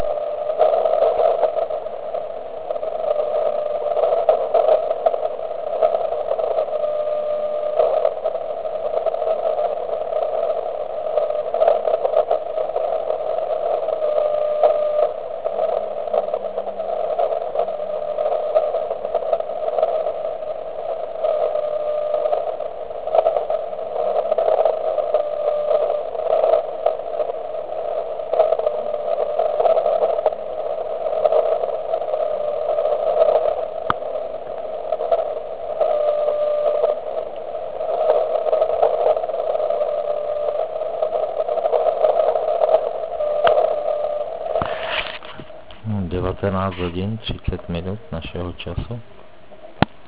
Vysílal zkušebně na 3536.1 KHz.
V Liberci: